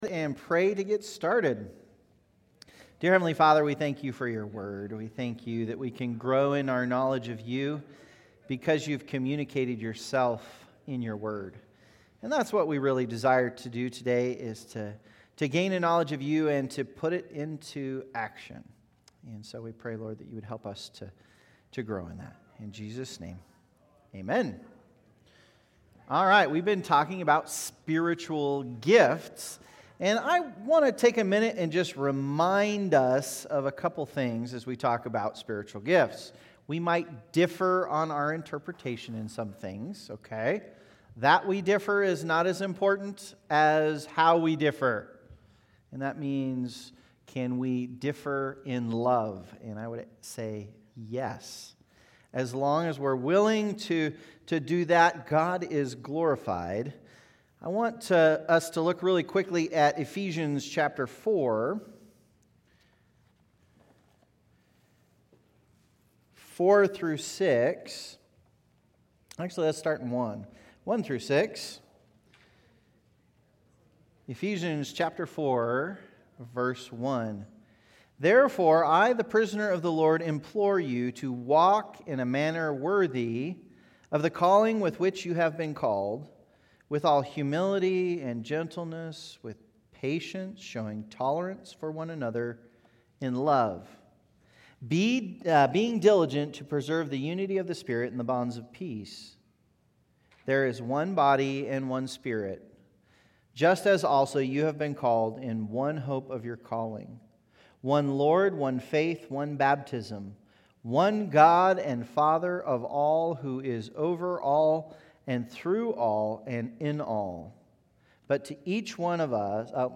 Adult Sunday School - 12/1/24